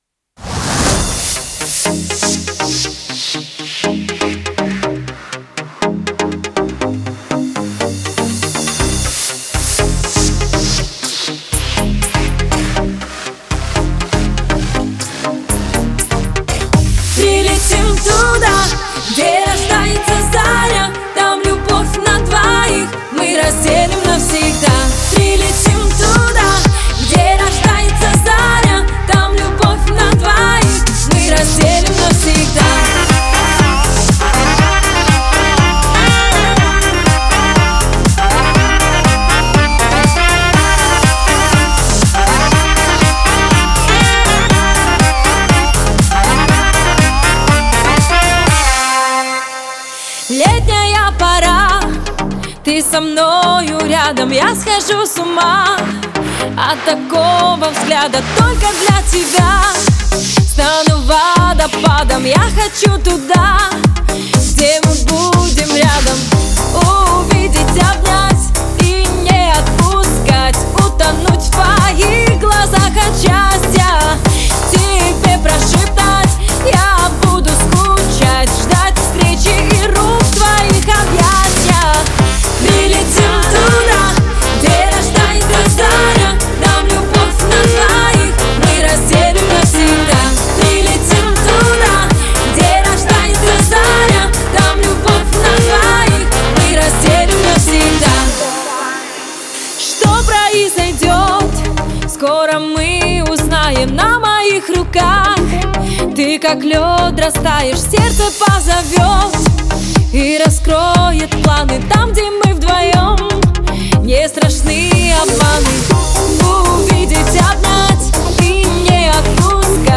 Летняя пора (Pop)
На мастере только Invisible Limiter.